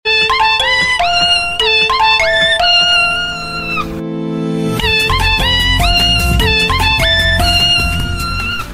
Kitten Meow Ringtone Phone 😁 Sound Effects Free Download